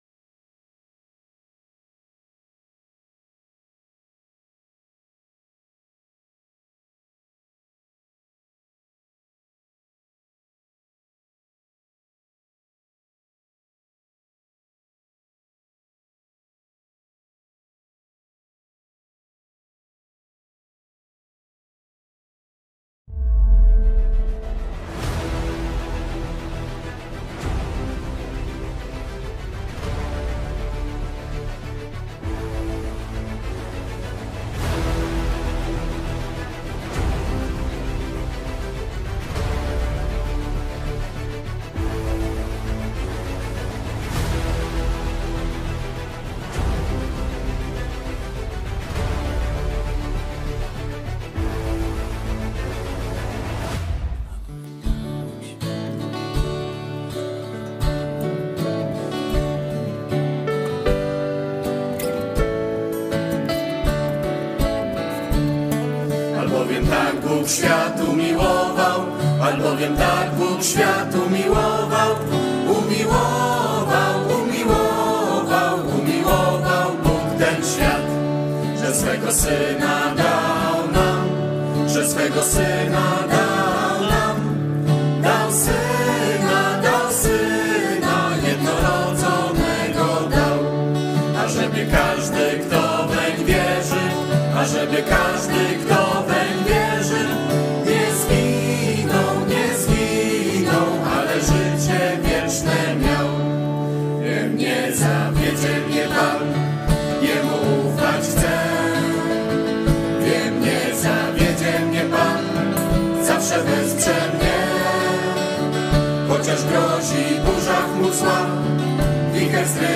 Nauczanie